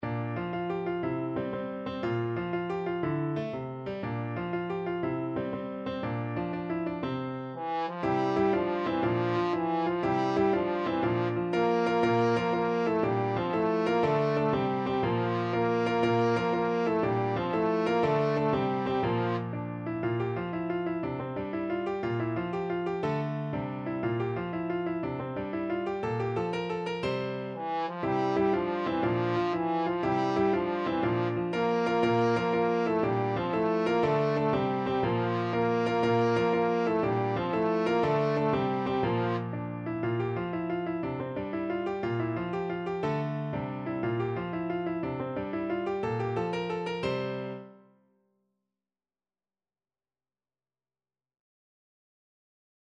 Trombone
6/8 (View more 6/8 Music)
F major (Sounding Pitch) (View more F major Music for Trombone )
With energy .=c.120
Classical (View more Classical Trombone Music)